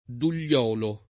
[ dul’l’ 0 lo ]